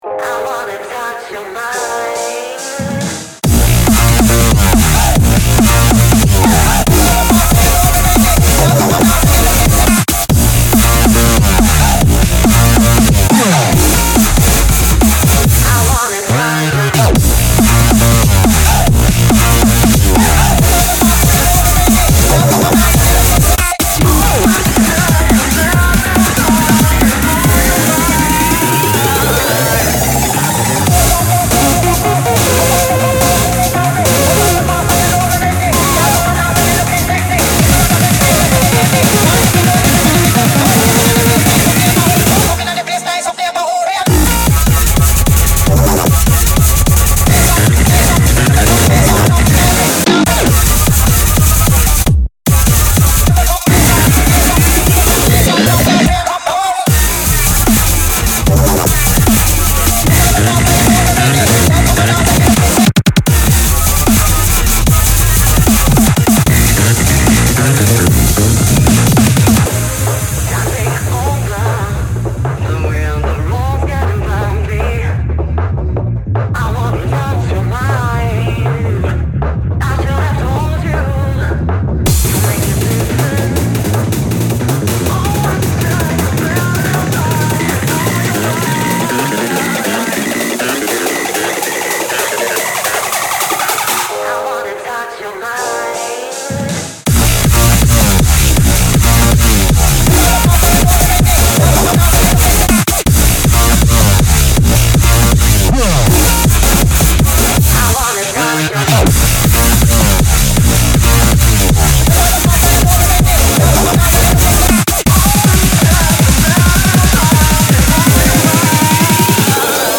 BPM140-160
Audio QualityPerfect (High Quality)
L - Gradual speedups from 140-->150 and 150-->160